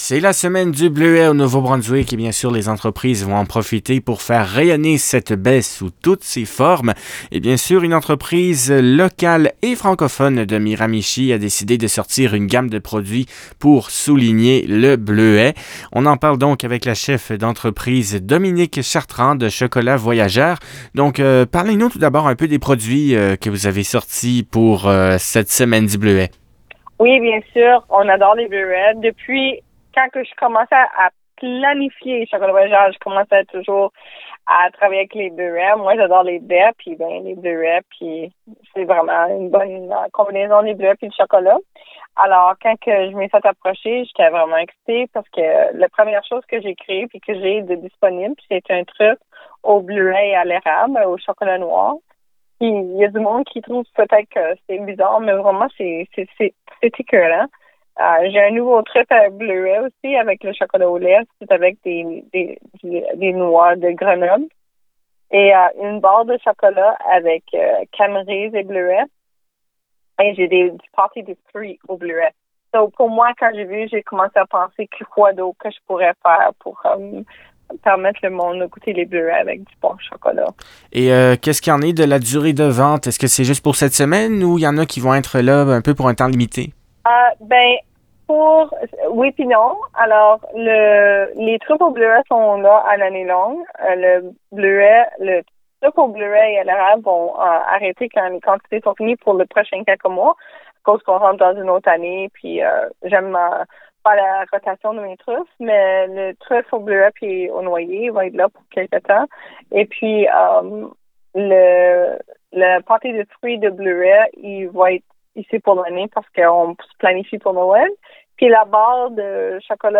Une entrevue